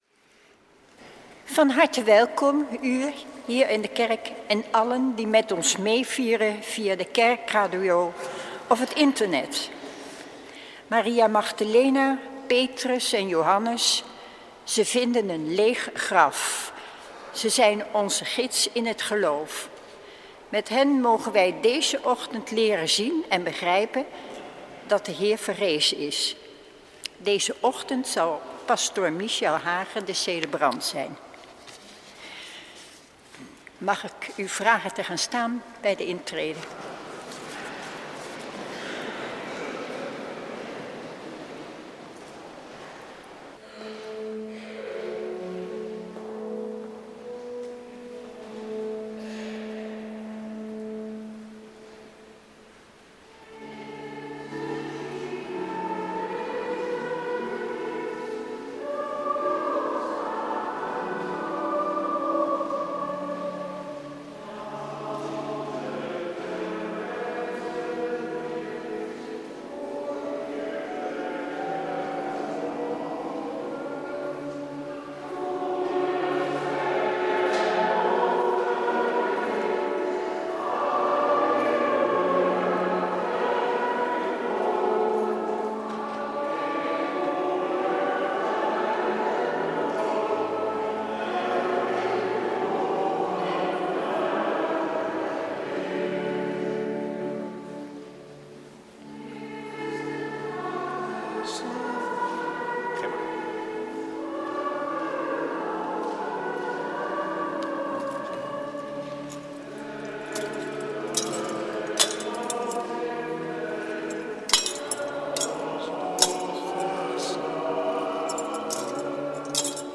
Preek tijdens de Paaswake en Paaszondag, jaar C, 30/31 maart 2013 | Hagenpreken
Eucharistieviering beluisteren vanuit de St. Willibrordus te Wassenaar (MP3)